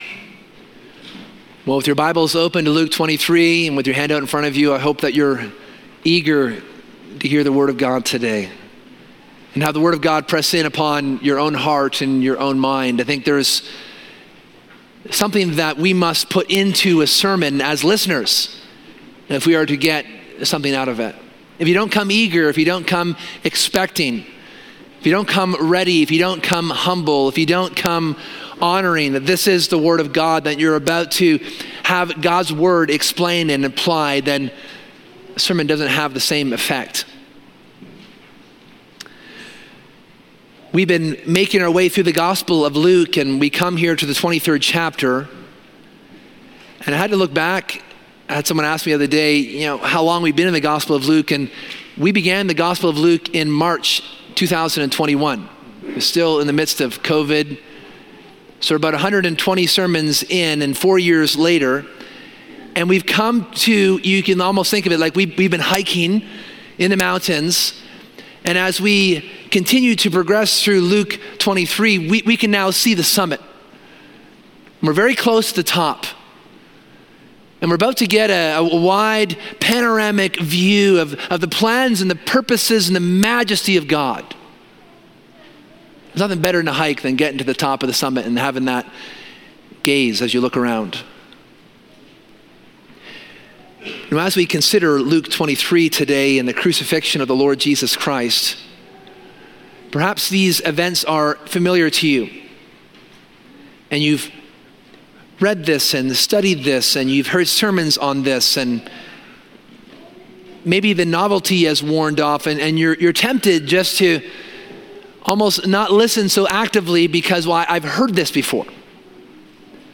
This sermon explores the profound events of Jesus’ crucifixion, highlighting three defining truths: Jesus’ innocence, his compassion, and his identity as the…